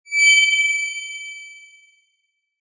輝くときなどの光の演出が欲しいときの効果音。